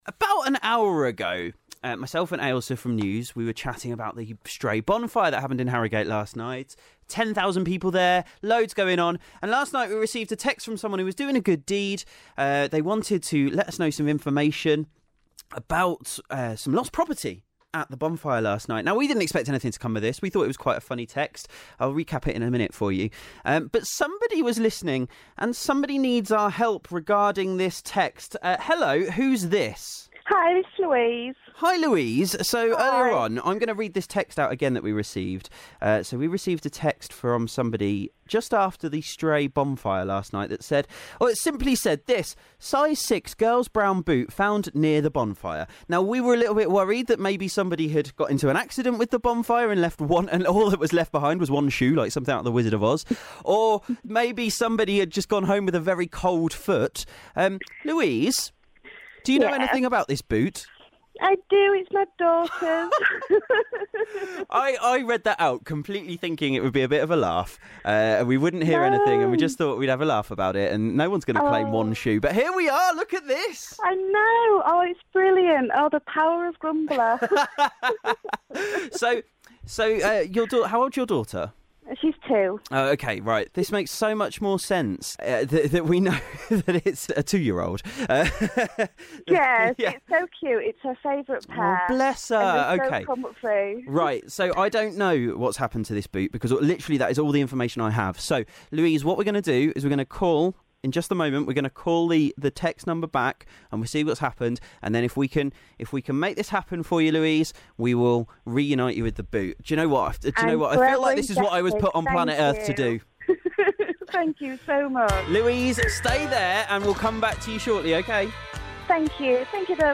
We received a cryptic text following the Stray Bonfire that a boot had been found. We thought nothing of it, even had a laugh about the strange text on air... then received this call...